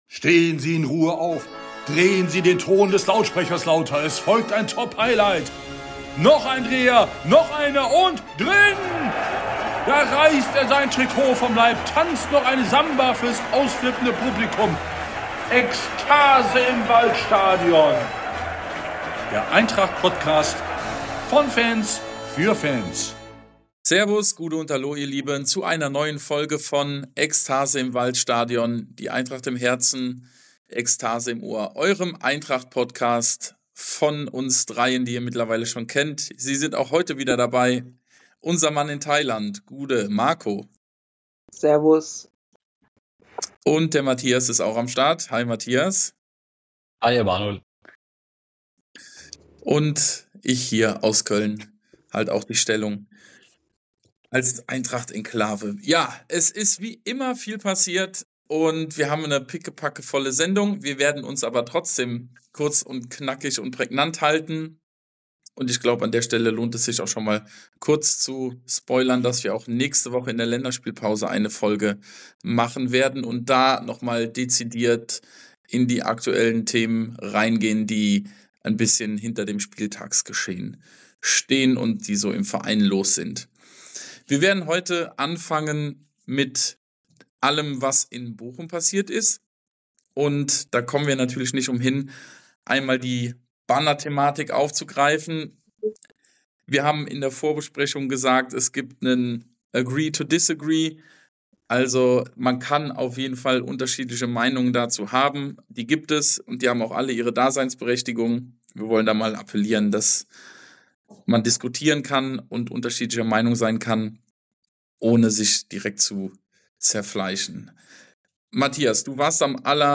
Hört rein, wenn wir wieder leidenschaftlich analysieren, diskutieren und ein bisschen philosophieren – mit der gewohnten Mischung aus Emotion, Fachwissen und einer Prise Wahnsinn.